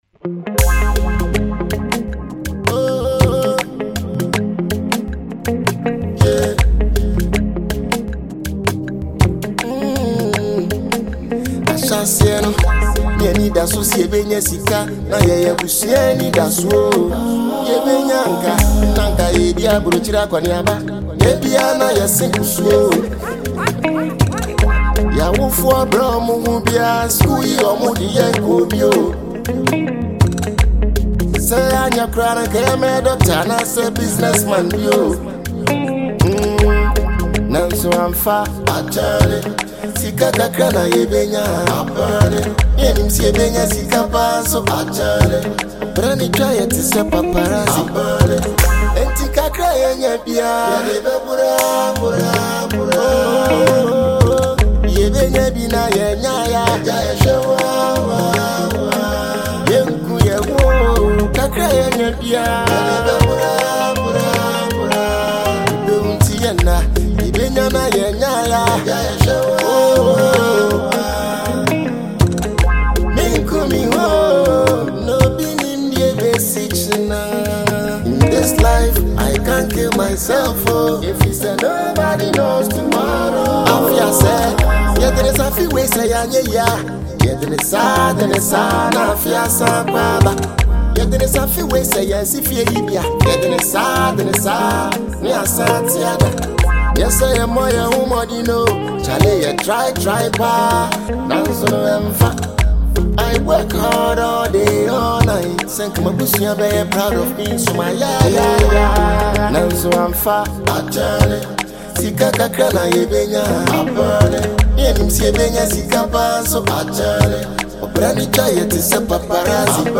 Ghana Naija Afrobeats